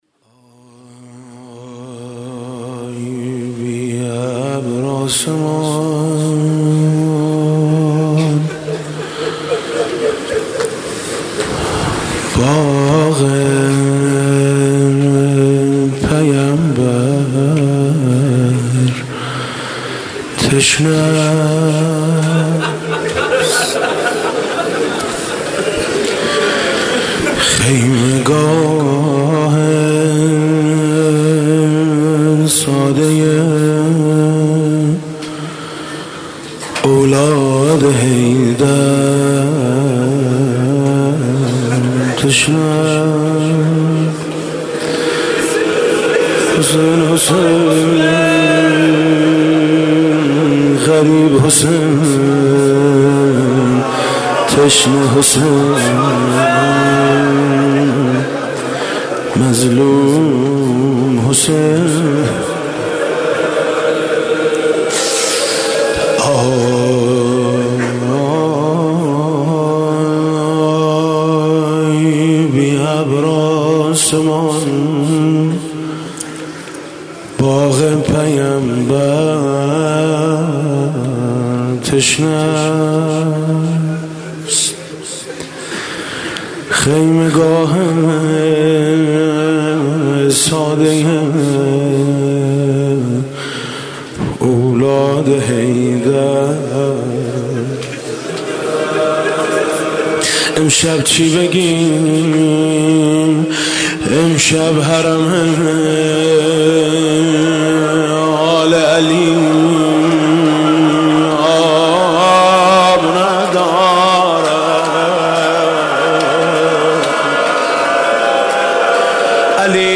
مداحی میثم مطیعی در شب هفتم محرم۹۷
روضه مقتل خوانی